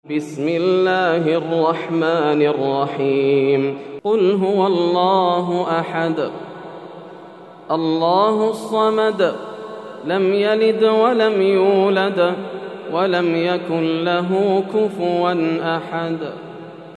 سورة الإخلاص > السور المكتملة > رمضان 1431هـ > التراويح - تلاوات ياسر الدوسري